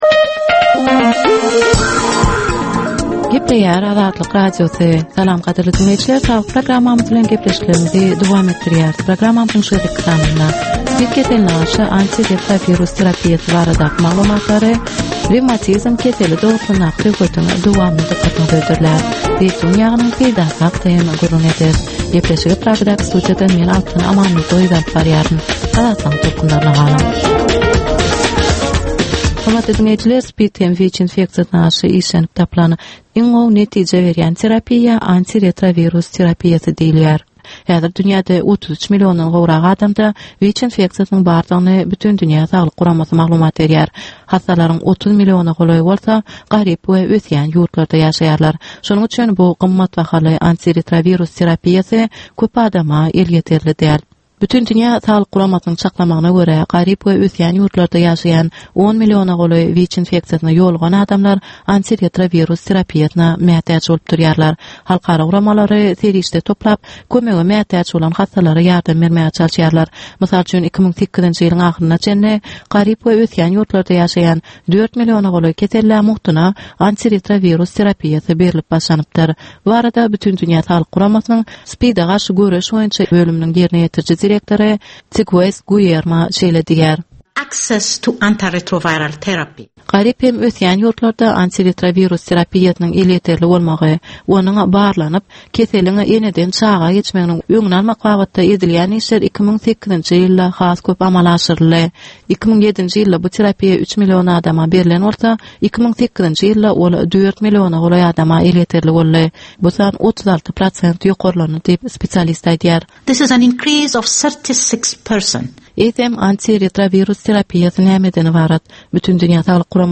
Ynsan saglygyny gorap saklamak bilen baglanyşykly maglumatlar, täzelikler, wakalar, meseleler, problemalar we çözgütler barada 10 minutlyk ýörite gepleşik.